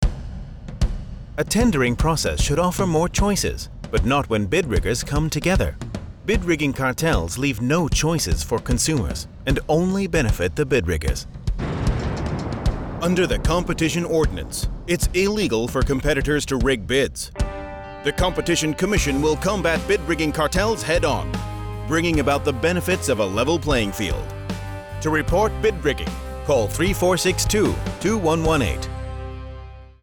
Radio Announcements